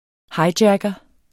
Udtale [ ˈhɑjˌdjagʌ ]